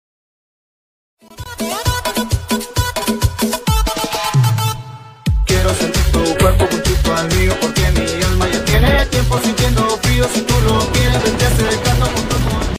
(con cumbia)